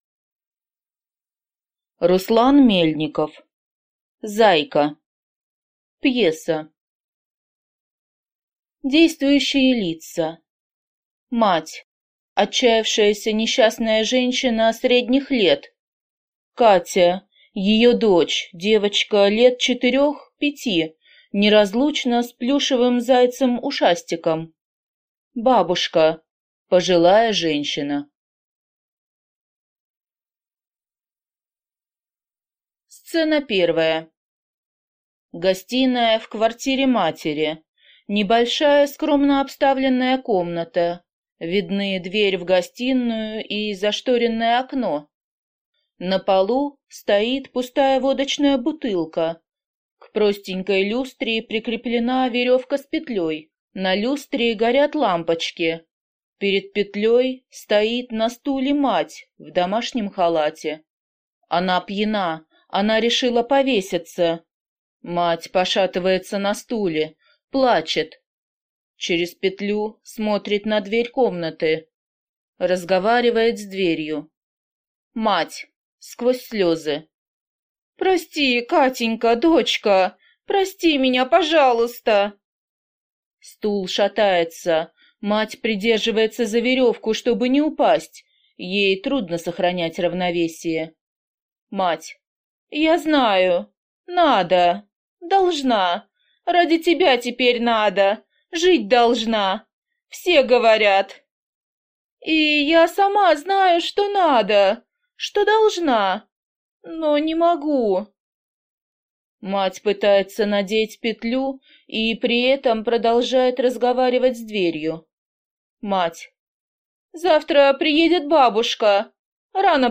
Аудиокнига Зайка | Библиотека аудиокниг